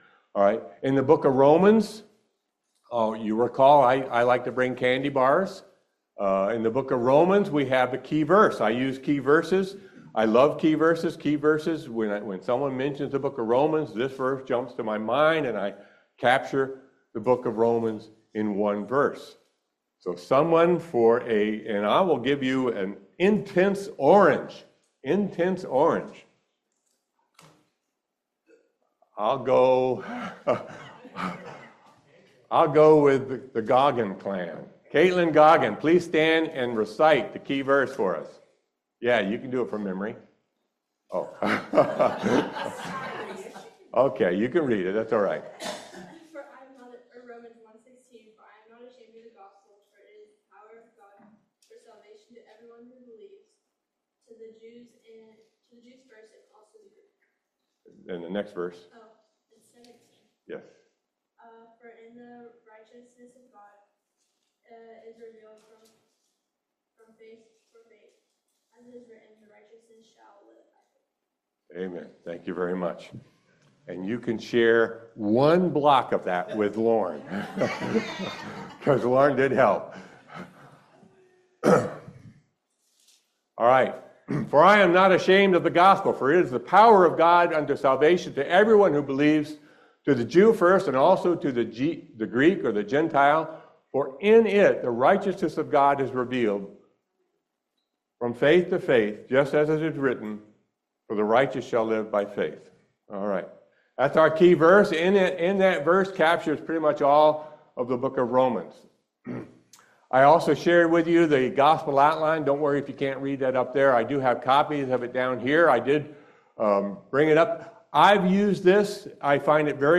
Romans 12:17-21 Service Type: Family Bible Hour Exercise spiritual gifts to build up the body.